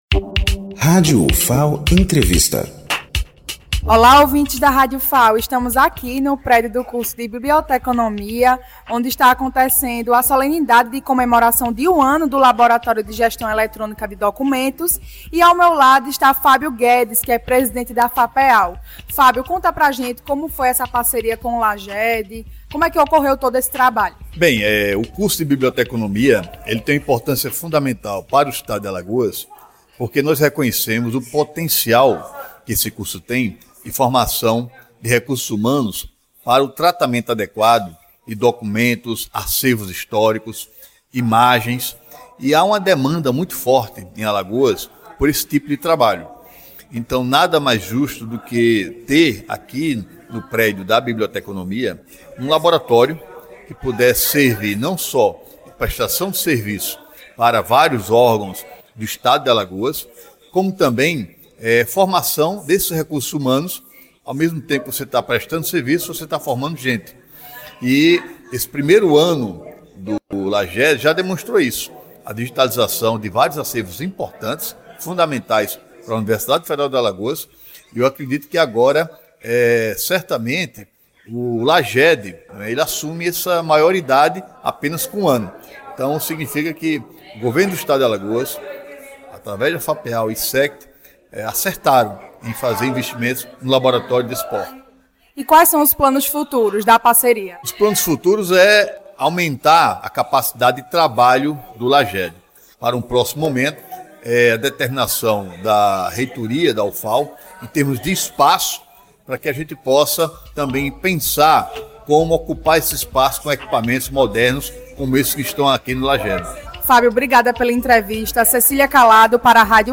Entrevista com Fábio Guedes, presidente da Fundação de Amparo à Pesquisa do Estado de Alagoas (Fapeal).
No prédio do curso de Biblioteconomia, a Rádio Ufal acompanha a solenidade de comemoração de um ano de atuação do Laboratório de Gestão Eletrônica de Documentos (LAGED).